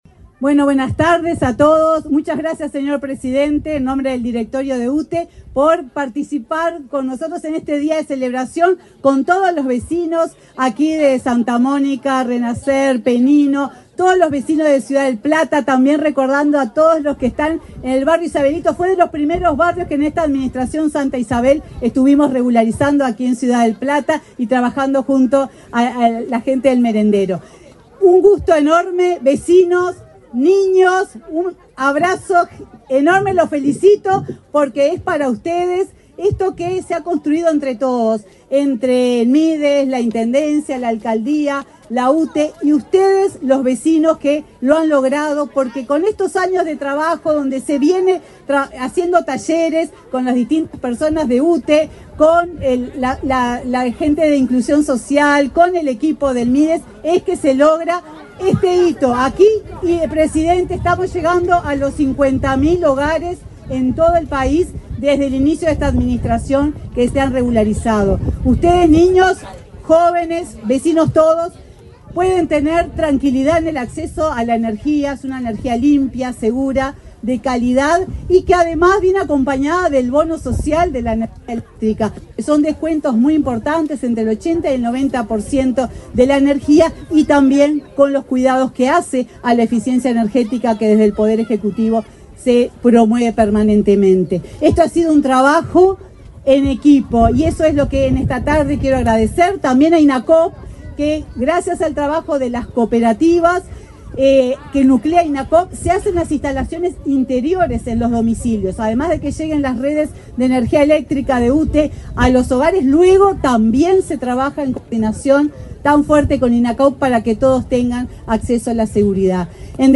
La presidenta de UTE, Silvia Emaldi, se expresó durante el acto de inauguración de obras de electrificación en Ciudad del Plata, departamento de San